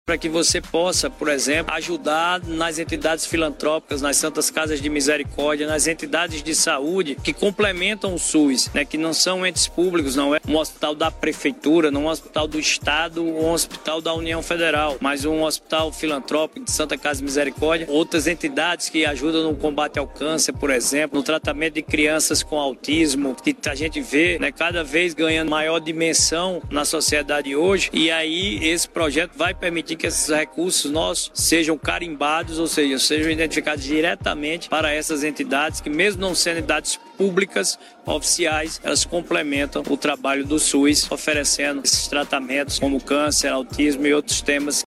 Ouça o senador Efraim Filho:
Senador-Efraim-Filho-entidades-filantropicas.mp3